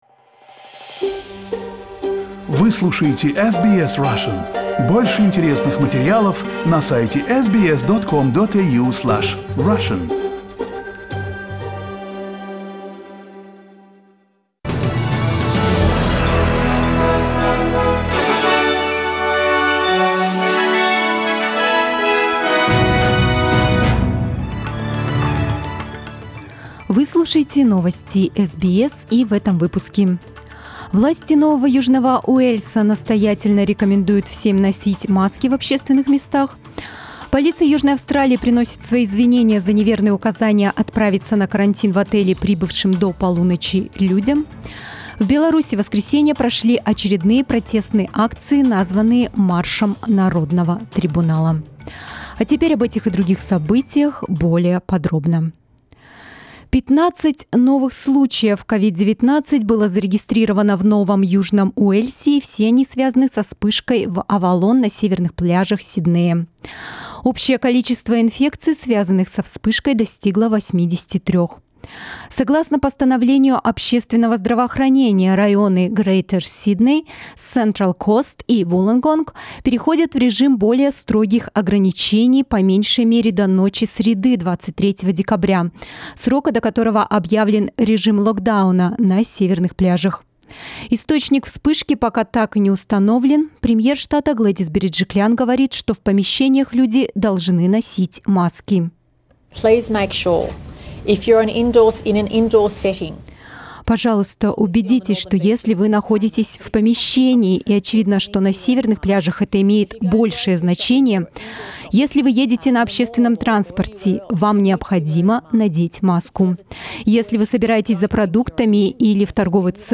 News Bulletin in Russian, December 21